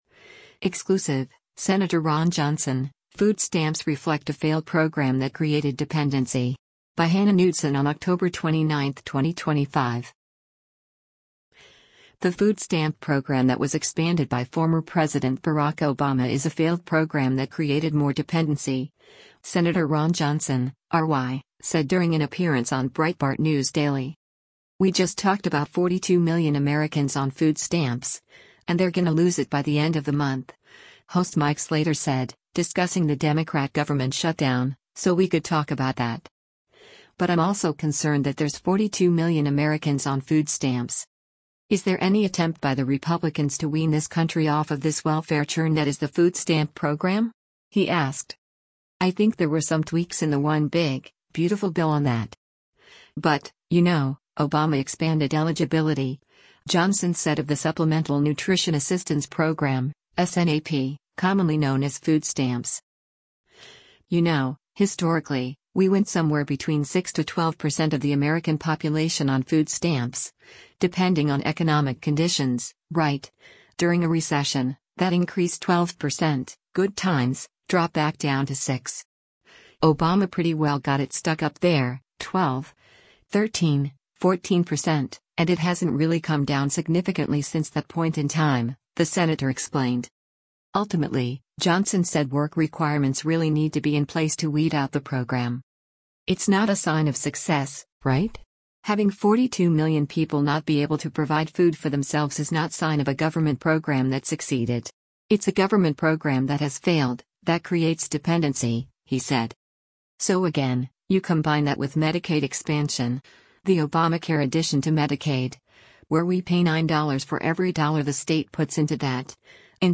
The food stamp program that was expanded by former President Barack Obama is a failed program that created more dependency, Sen. Ron Johnson (R-WI) said during an appearance on Breitbart News Daily.
Breitbart News Daily airs on SiriusXM Patriot 125 from 6:00 a.m. to 9:00 a.m. Eastern.